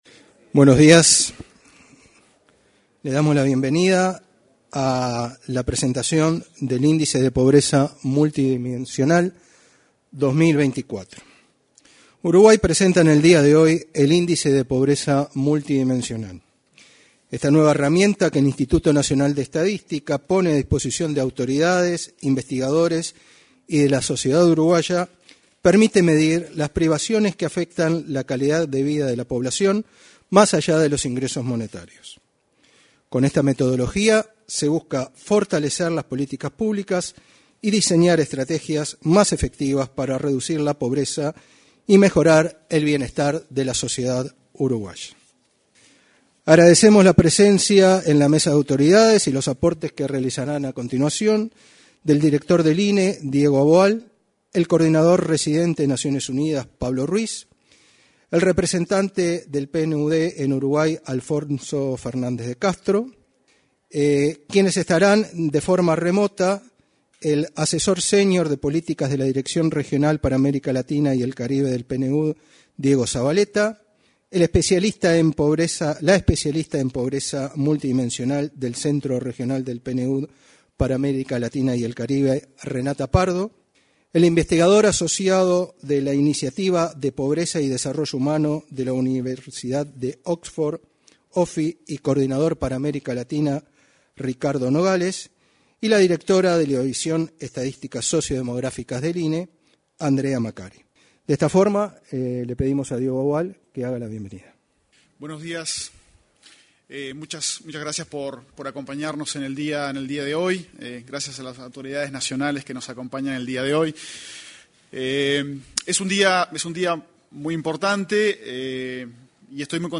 Este 19 de febrero, se realizó, en el salón de actos de la Torre Ejecutiva, la presentación del informe del índice de pobreza multidimensional 2024.